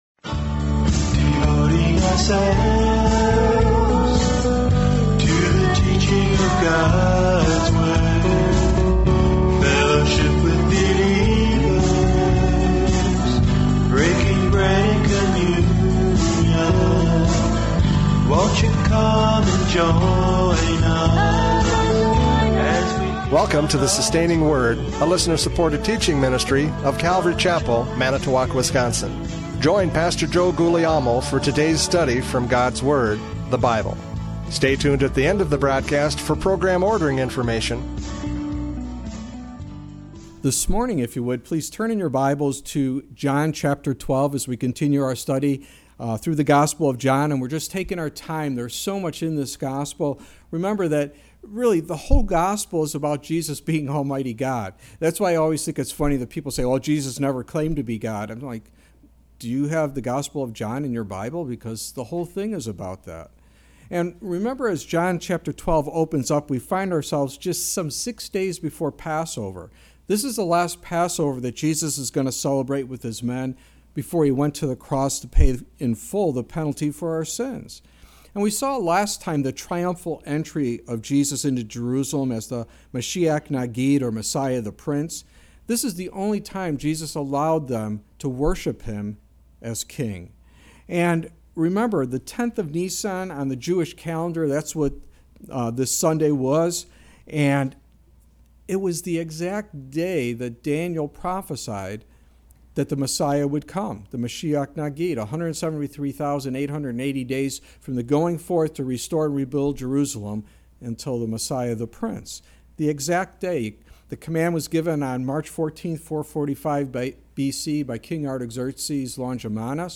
John 12:23-43 Service Type: Radio Programs « John 12:12-22 The Triumphant Entry!